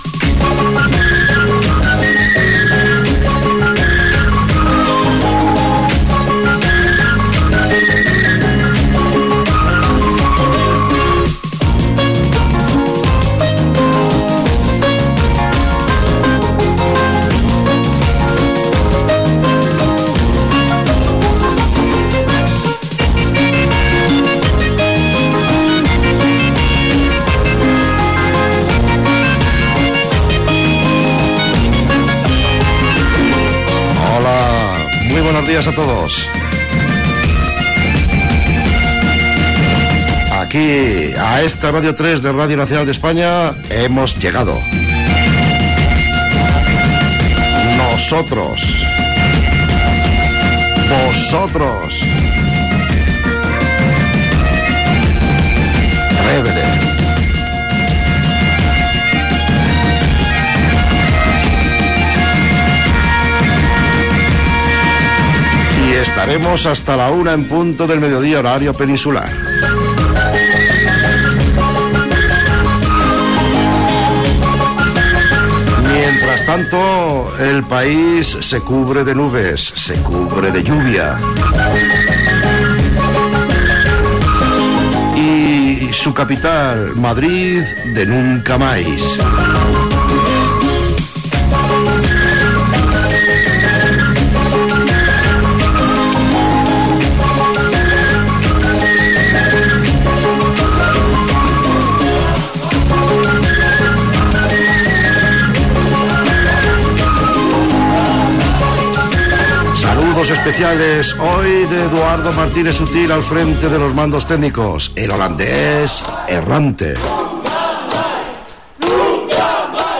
cbd0e65060b37fde71bdfb3f05dec0788bbd8aae.mp3 Títol Radio 3 Emissora Radio 3 Cadena RNE Titularitat Pública estatal Nom programa Trébede Descripció Sintonia, presentació amb esment al moviment "Nunca mais", tema musical i comentari. Hora i identificació, informació de la manifestació a Madrid "Nunca mas", demanda d'una oïdora sobre Internet i tema musical Gènere radiofònic Musical